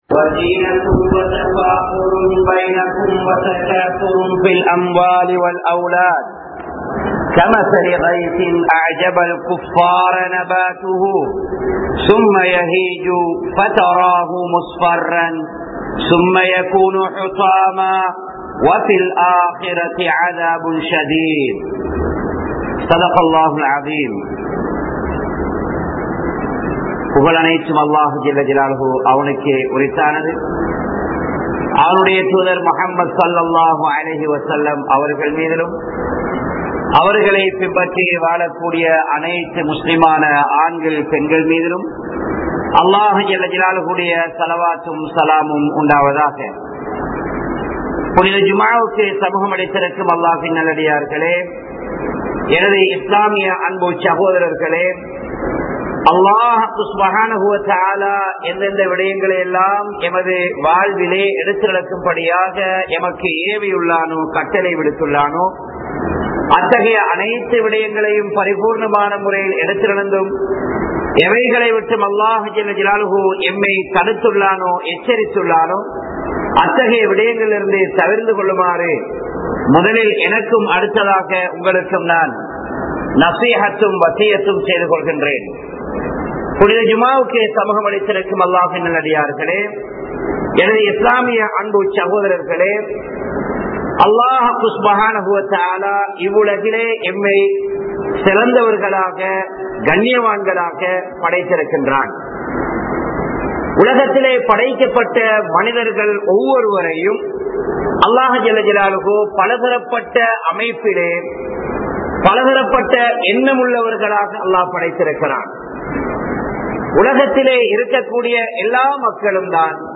Em Naattai Neasippoam (எம் நாட்டை நேசிப்போம்) | Audio Bayans | All Ceylon Muslim Youth Community | Addalaichenai